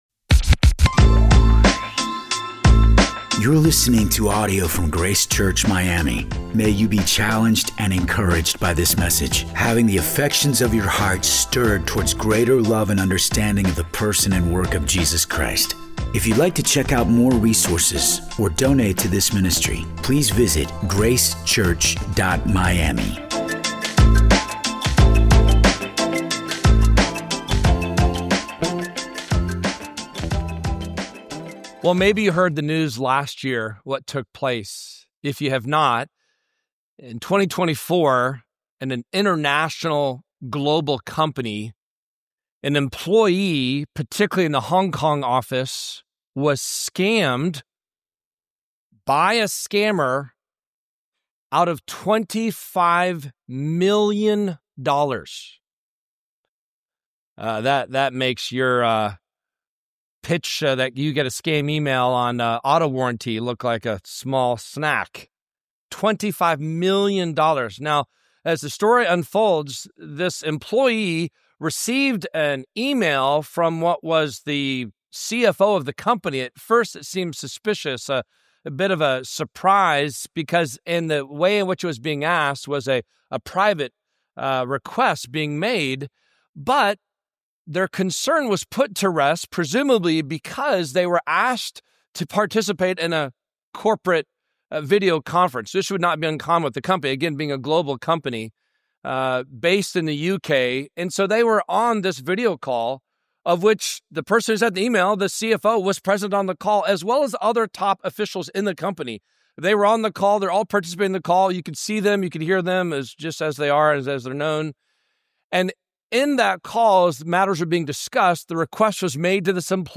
Grace Church Miami - Sermons Podcast - Exposing the Deepfakes within Christianity | Free Listening on Podbean App